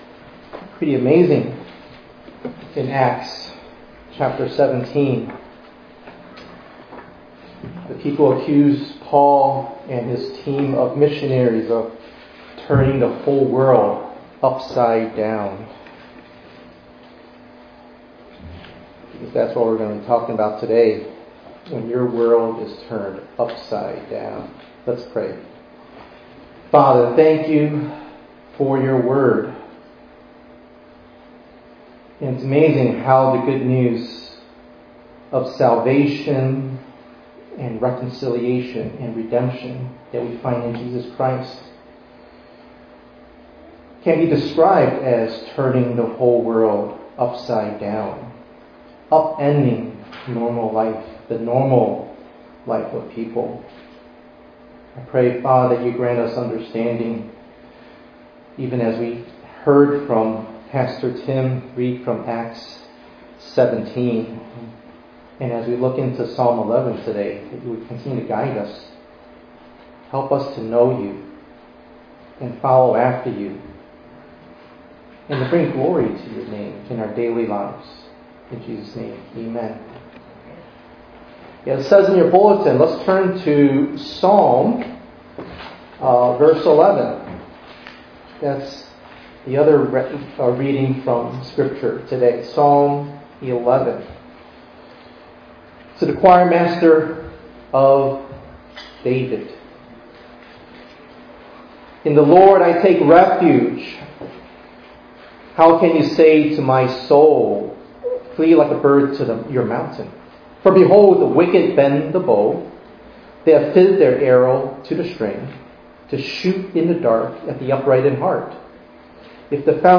3_20_22_ENG_Sermon.mp3